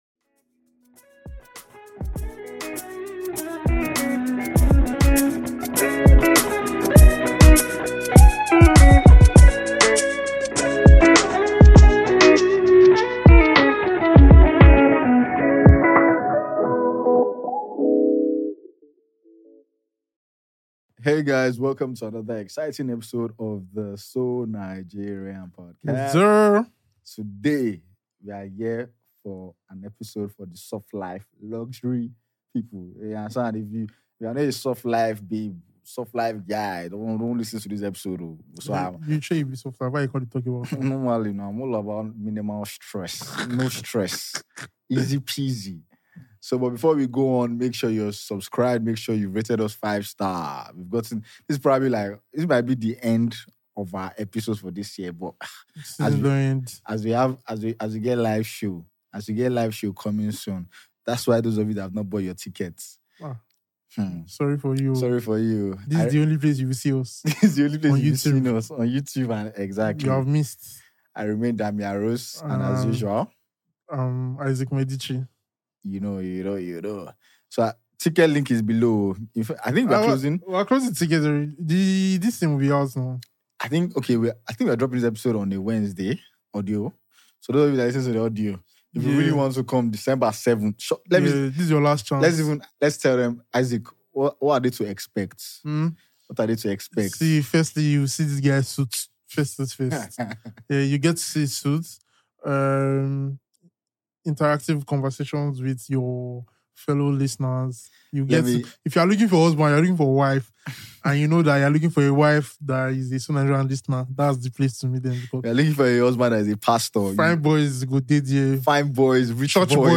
Whether you're dealing with a toxic boss or serious life issues, this podcast is the perfect way to unwind and laugh along with two funny and insightful hosts.